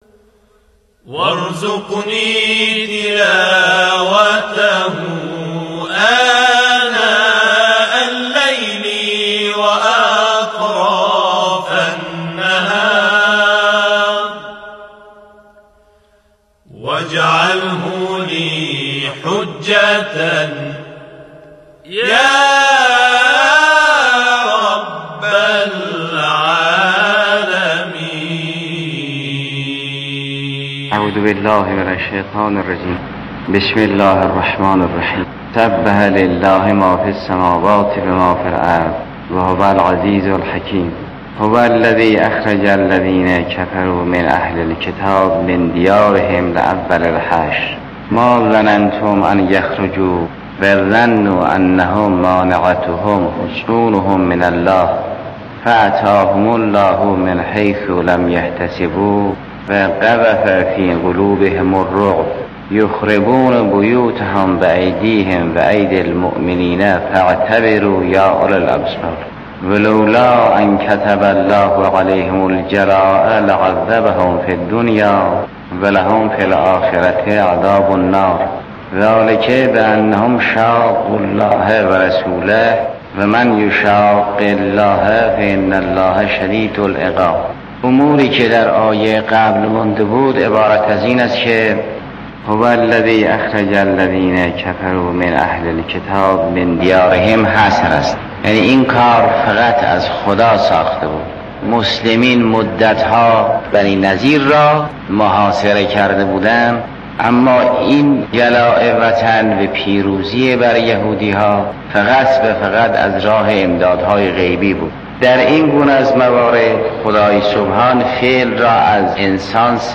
«سروش هدایت» با محوریت سخنرانی‌های آیت‌الله العظمی جوادی آملی از شبکه رادیویی قرآن پخش می‌شود.